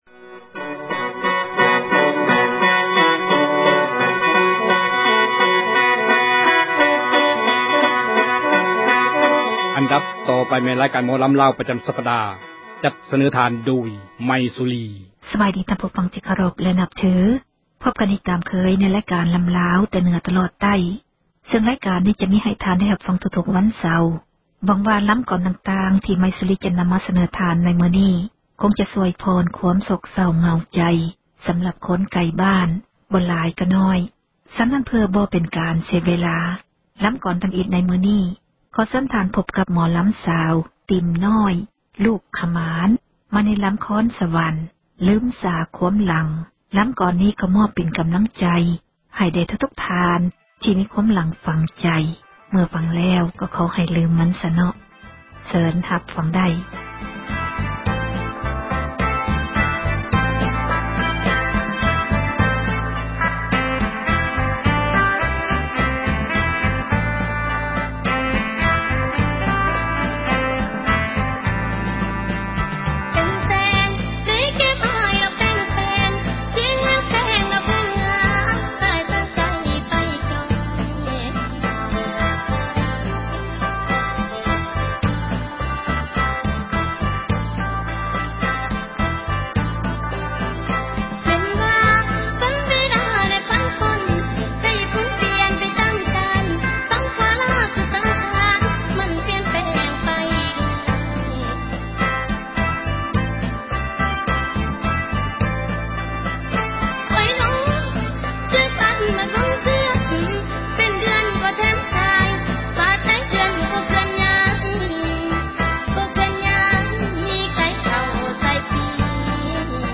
ຣາຍການ ໝໍລໍາລາວ ປະຈໍາສັປດາ ຈັດສເນີທ່ານ ໂດຍ